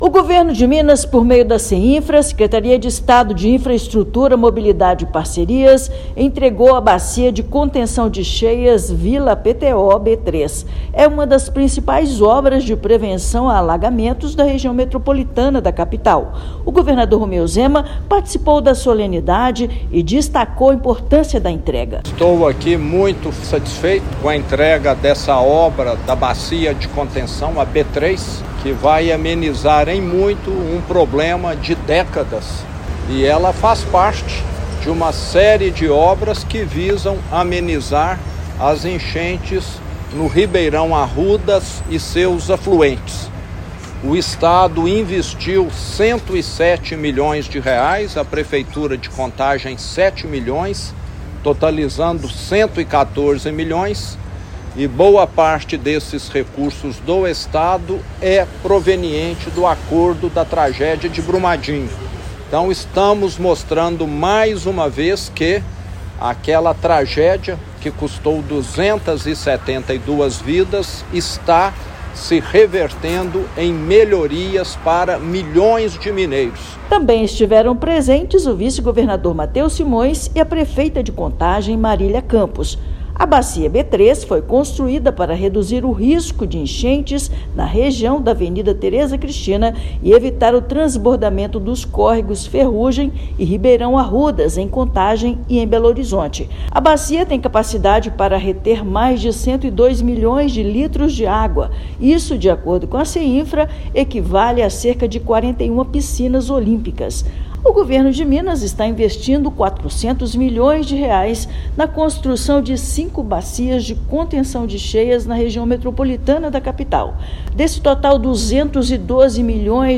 Estrutura tem capacidade para mais de 102 milhões de litros de água e integra conjunto de obras para controle de cheias do Córrego Ferrugem. Ouça matéria de rádio.